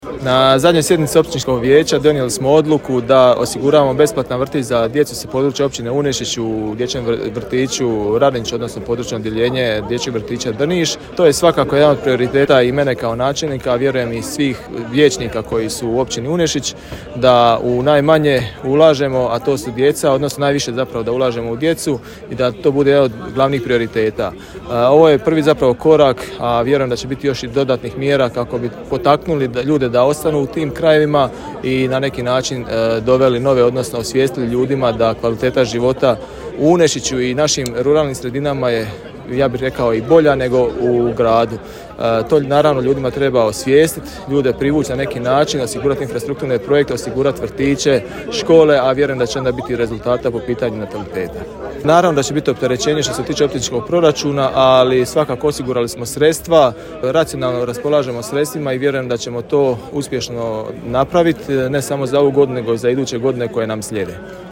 Riječ je o 22 djece, doznajemo detalje od načelnika Marka Parata: